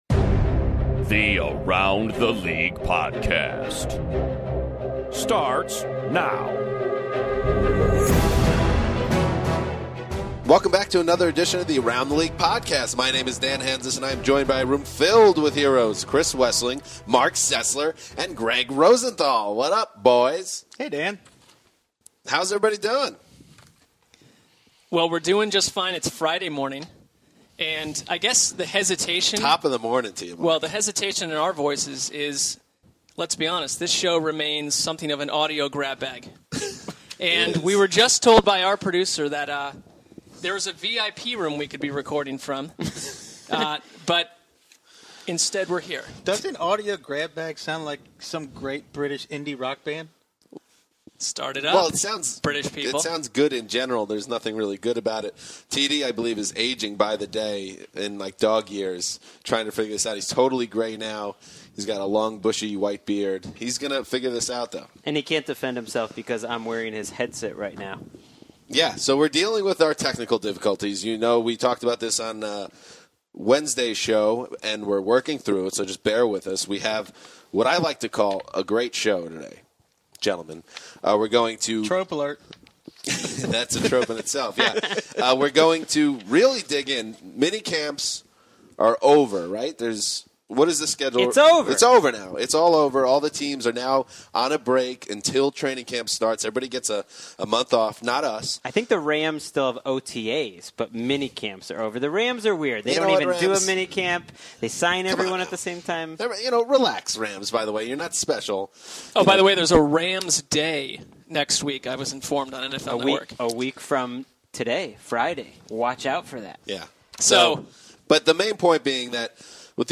The heroic foursome also react to the latest news from around the league, including the Tom Brady and New York Jets' playbook fiasco, and the Atlanta Falcons’ new backup quarterback.